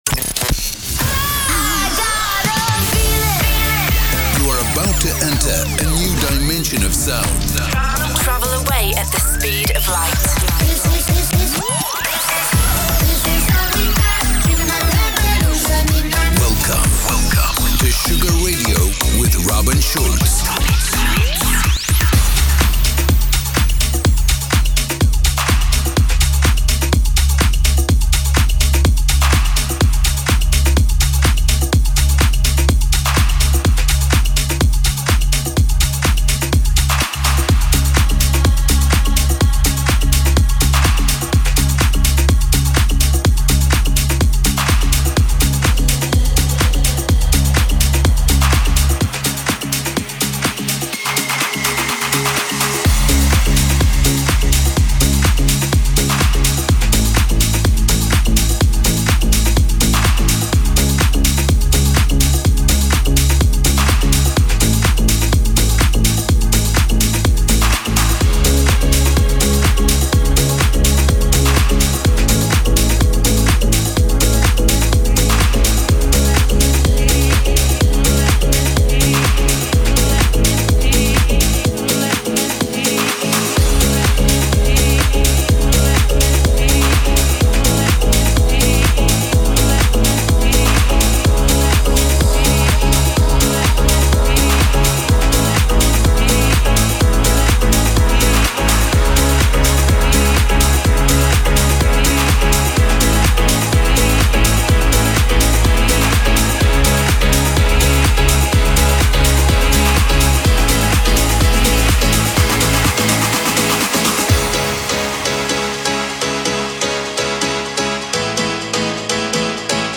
Uma hora com o melhor Tropical House do músico